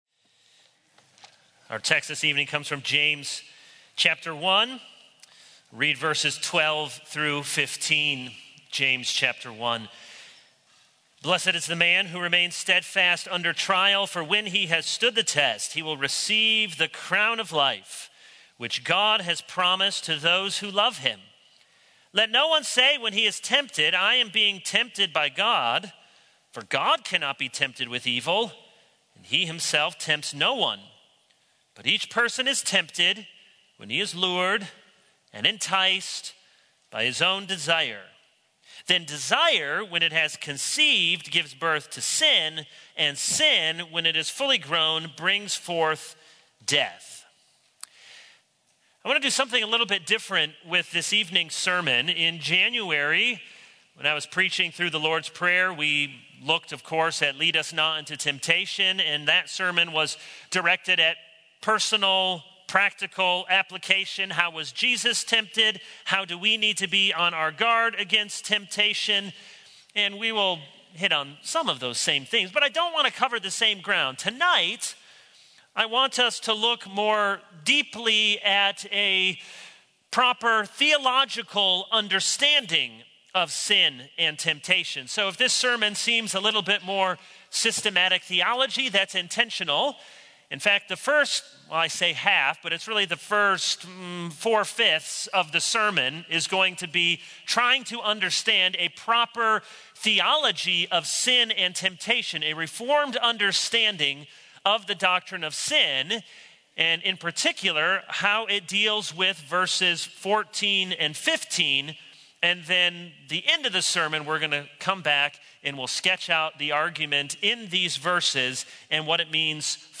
All Sermons Good News, Bad News 0:00 / Download Copied!